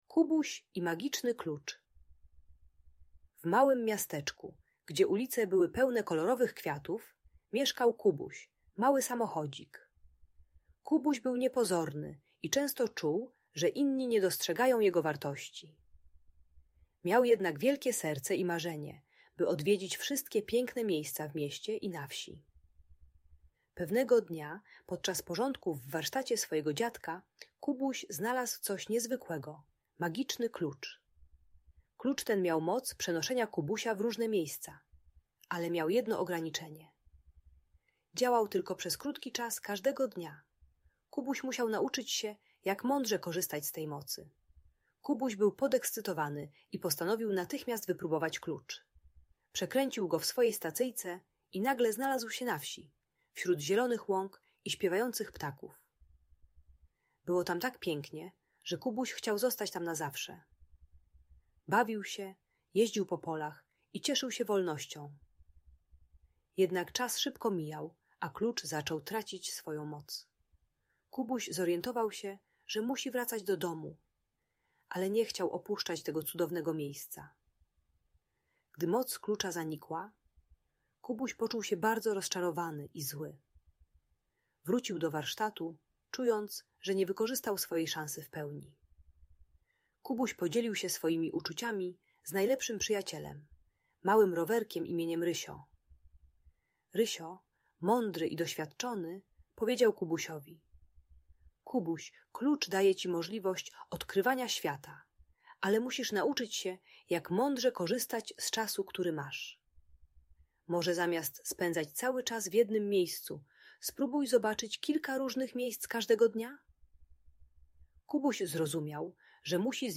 Kubuś i Magiczny Klucz - Audiobajka